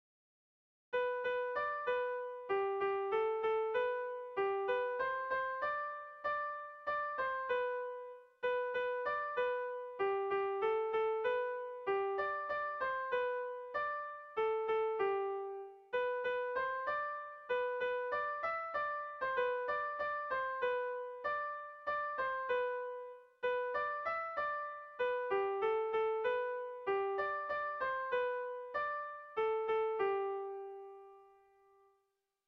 Erlijiozkoa
Zortziko handia (hg) / Lau puntuko handia (ip)
A1A2BA2